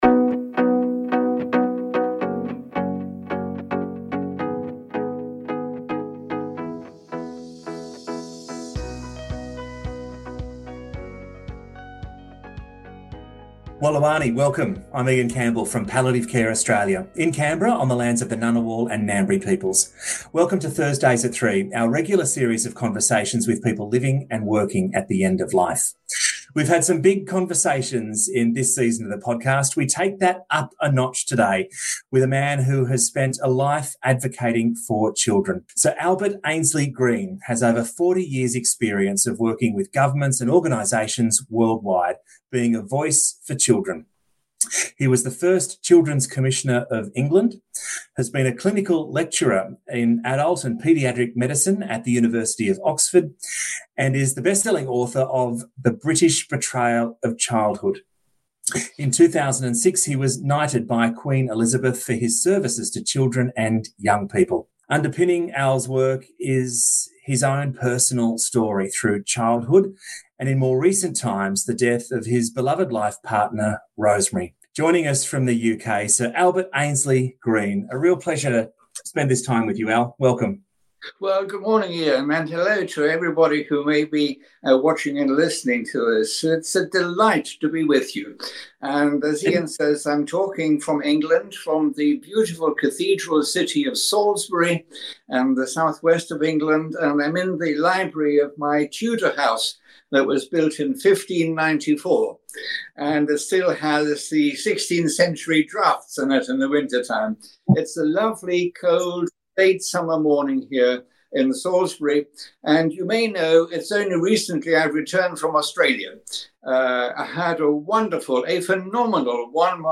Welcome to Thursdays@3 our regular series of conversations with people living and working at the end of life.